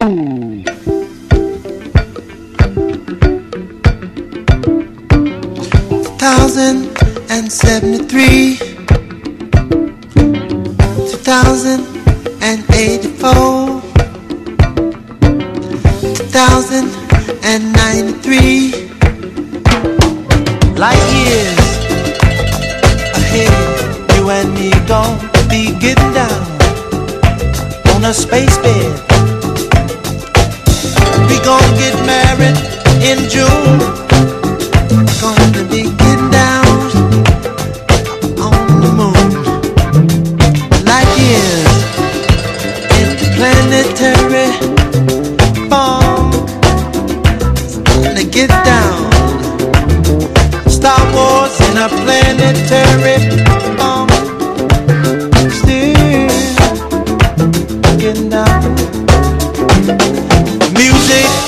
SOUL / SOUL / FREE SOUL / KIDS SOUL